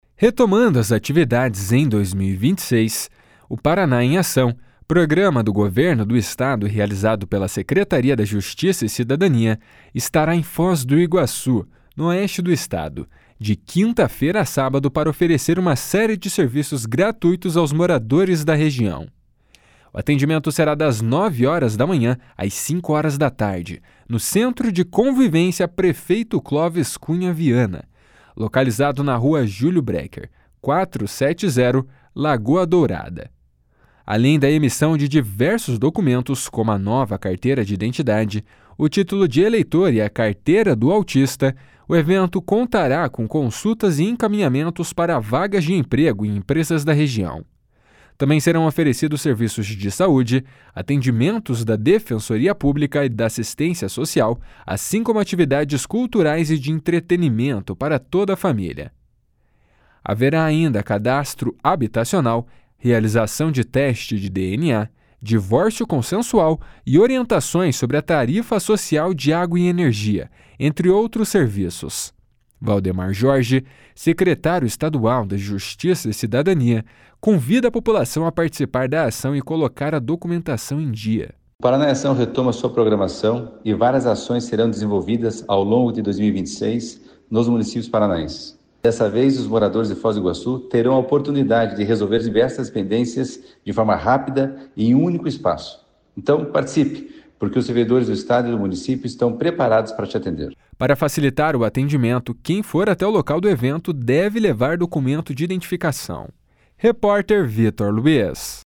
Valdemar Jorge, secretário estadual da Justiça e Cidadania, convida a população a participar da ação e colocar a documentação em dia. // SONORA VALDEMAR JORGE //